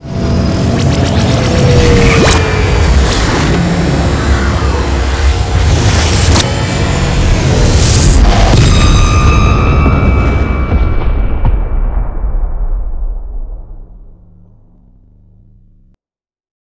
wormhole2.wav